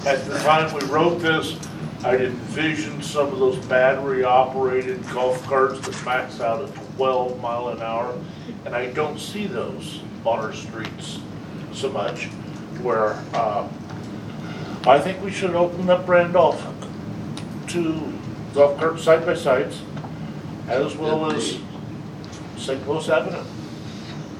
During Monday’s Vandalia City Council meeting, Alderman Bret Brosman again brought the matter up for discussion, saying that expansion to allow UTVs and Golf Carts on Randolph Street and the remainder of St. Louis Avenue should be the next step.